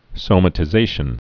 (sōmə-tĭ-zāshən)